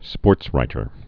(spôrtsrītər)